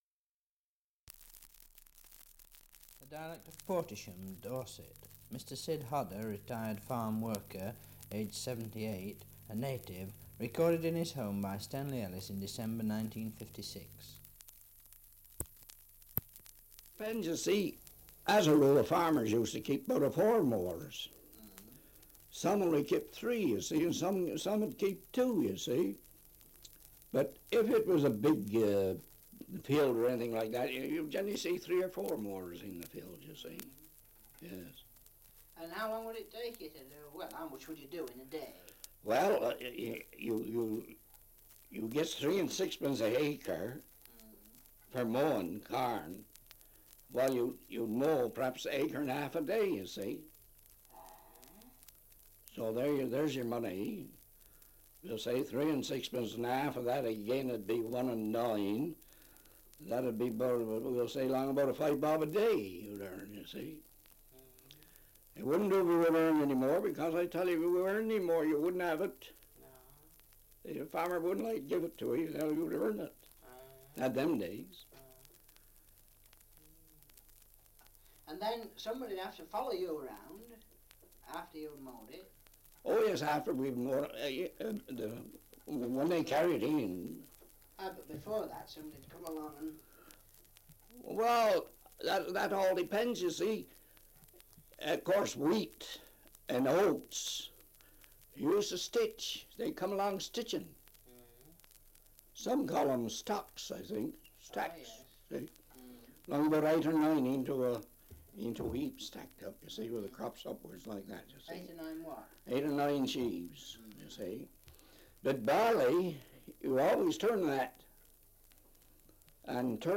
Survey of English Dialects recording in Portesham, Dorset
78 r.p.m., cellulose nitrate on aluminium